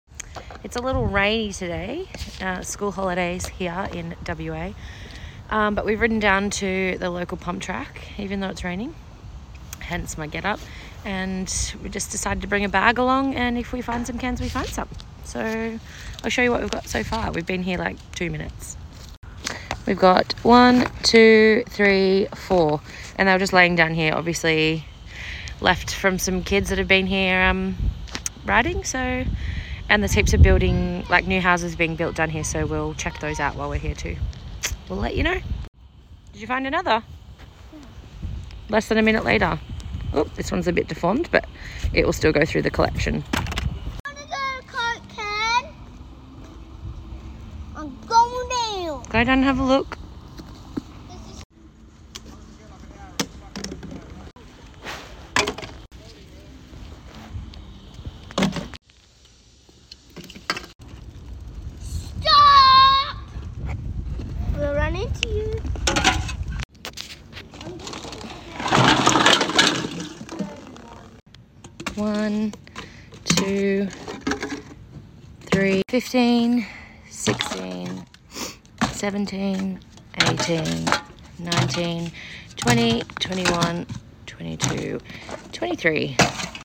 Cans to Couch- Rainy ride to the local pump track, via some new build rubbish piles.